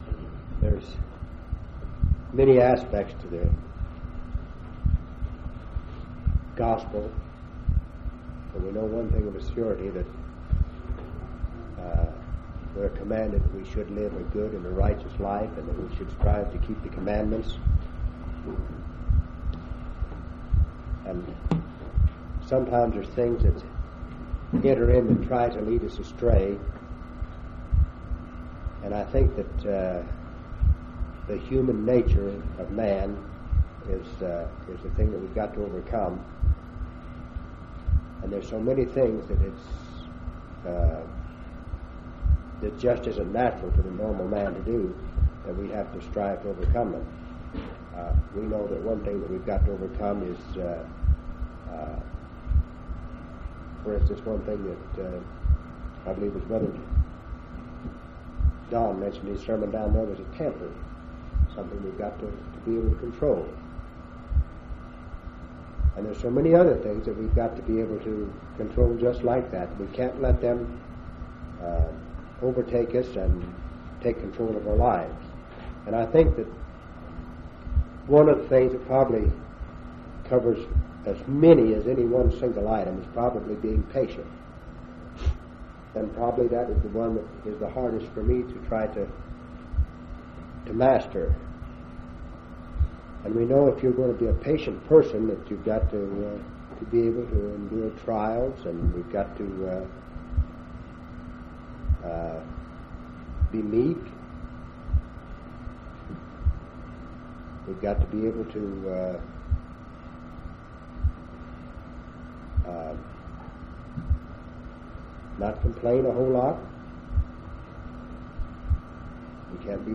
1/6/1980 Location: Grand Junction Local Event